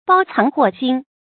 注音：ㄅㄠ ㄘㄤˊ ㄏㄨㄛˋ ㄒㄧㄣ
包藏禍心的讀法